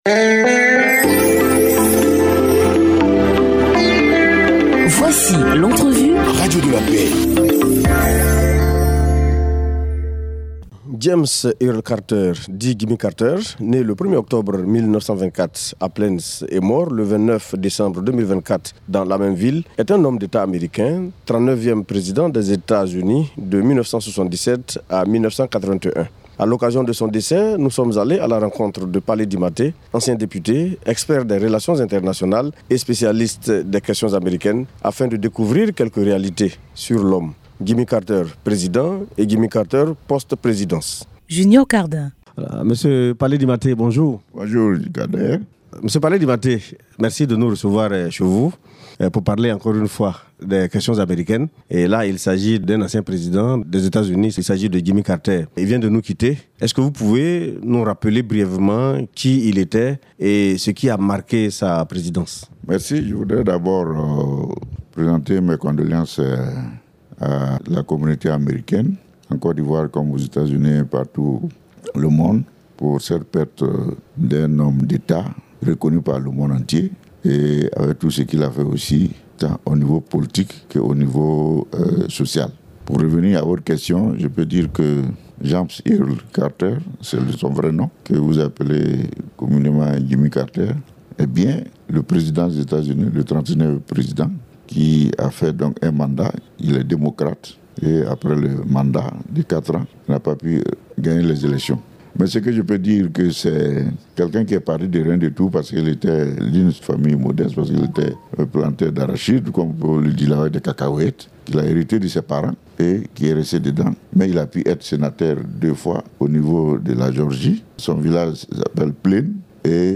Entrevue sur Radio de la Paix